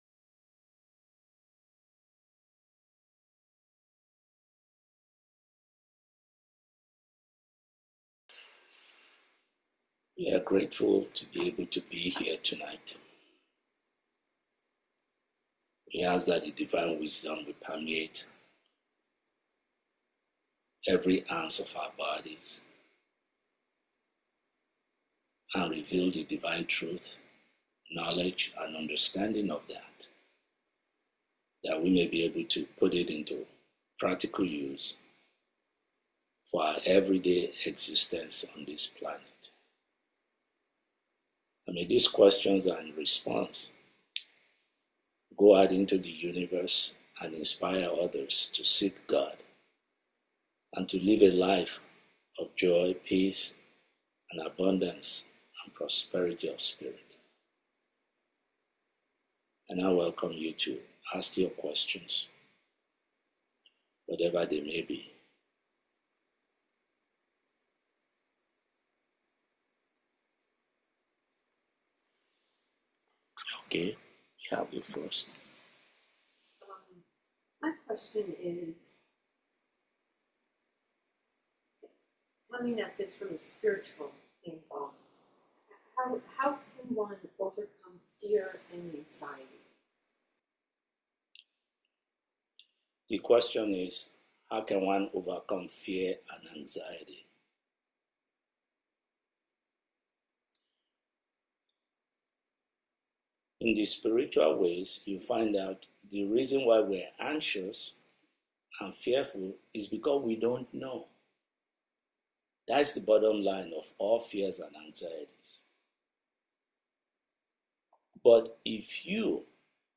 May 2025 Satsang